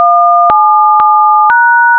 phone
phone.wav